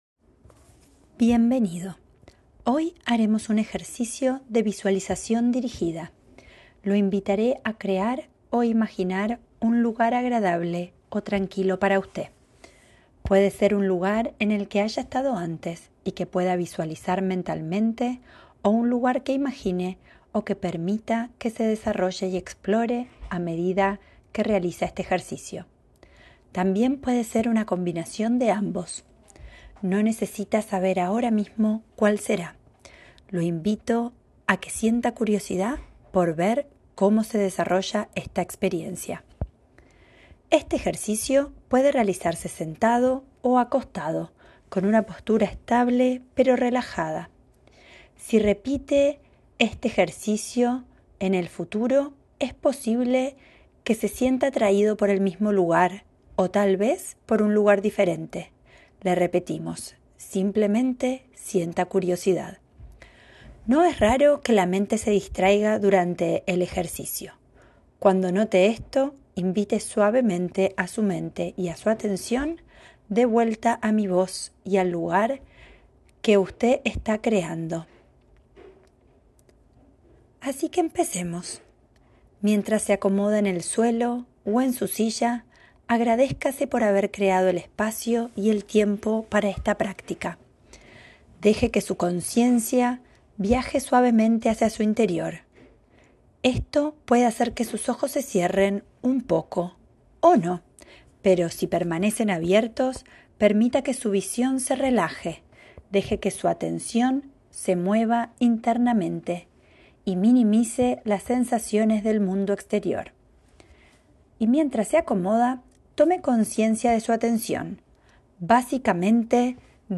Guided Imagery-Spanish - Life with Cancer
Guided-Imagery-Spanish.m4a